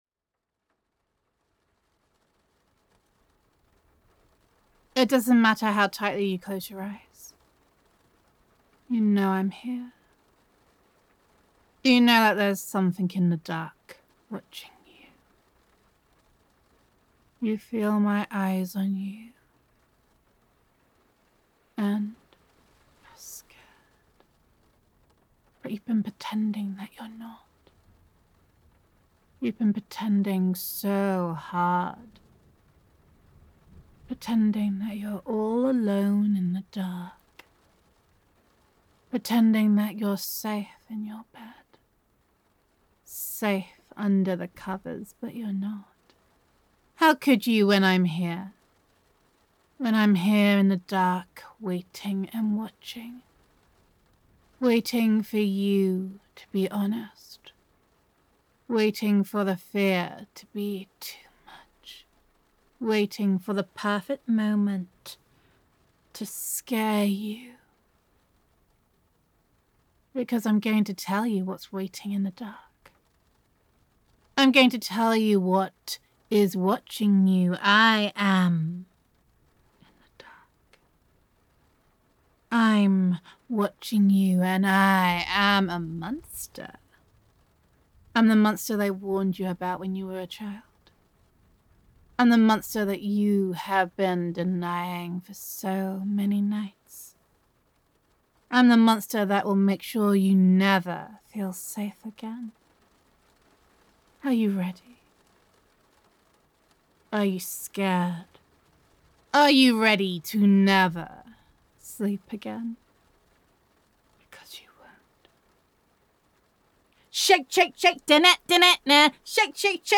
[F4A] Boogie Night Frights [Monster Roleplay]